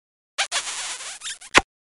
Smoochy Kiss Meme Sound Effect sound effects free download